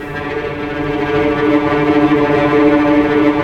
Index of /90_sSampleCDs/Roland LCDP08 Symphony Orchestra/STR_Vcs Bow FX/STR_Vcs Trem p